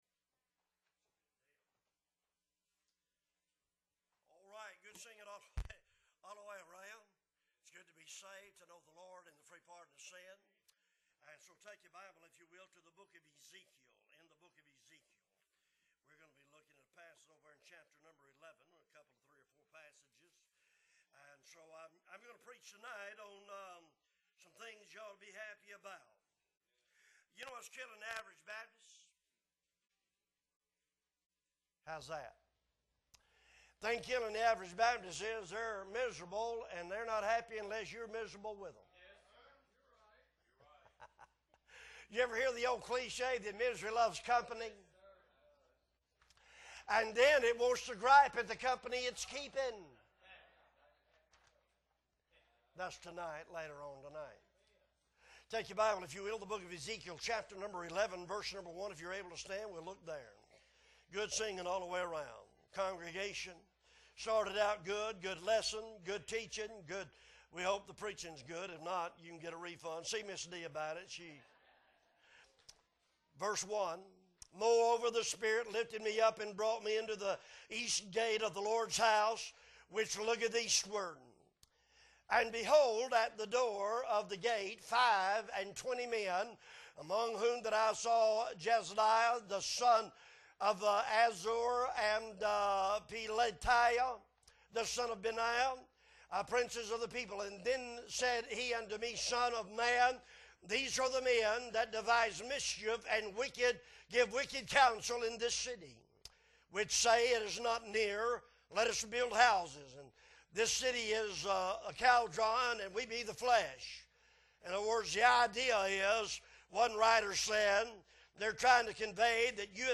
July 3, 2022 Sunday Morning - Appleby Baptist Church